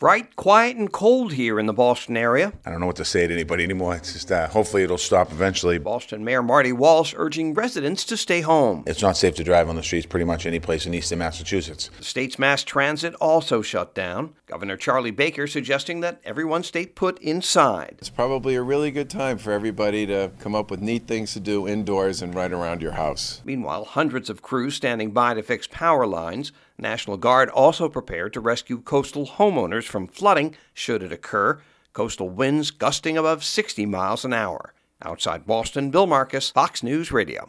(OUTSIDE BOSTON) FEB 15 – NEW ENGLAND RESIDENTS BEING TOLD TO STAY HOME UNTIL THE FOURTH BLIZZARD IN THREE WEEKS IS OVER. SOME SPOTS ALREADY GETTING ABOUT TWO FEET OF SNOW TO ADD TO THE SIX FEET ALREADY ON THE  GROUND.
4PM NEWSCAST –